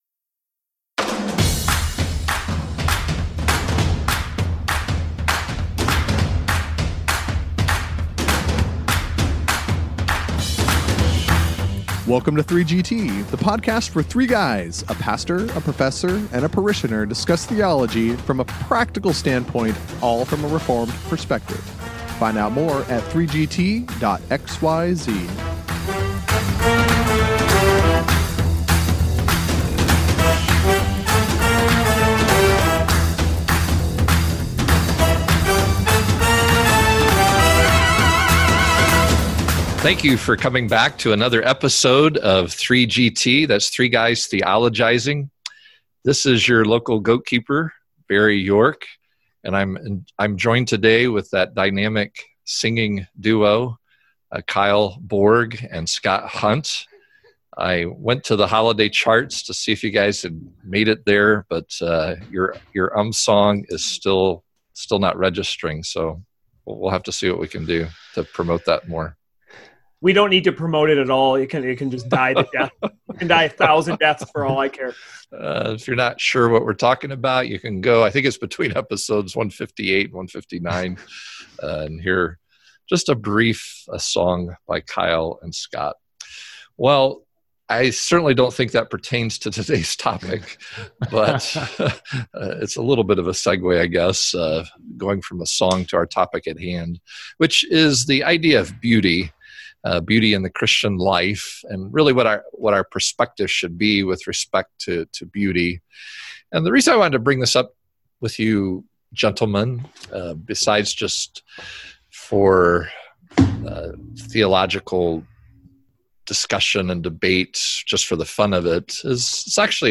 The 3GTers discuss the place of beauty in the Christian life, its subjective and objective components, its connection with the glory of God, the purpose beauty has in this world, and its application in a variety of settings. They end reflecting on the subject of fading beauty and its call to look to the glorious beauty of heaven that yet awaits us. And along the way they have some typical fun at each other’s expense!